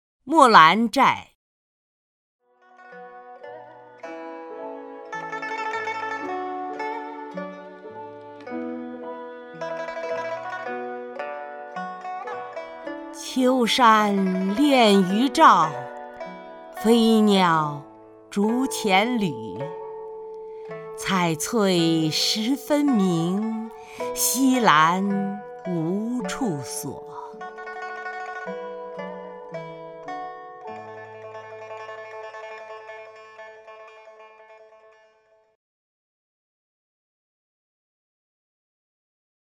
曹雷朗诵：《木兰柴》(（唐）王维) （唐）王维 名家朗诵欣赏曹雷 语文PLUS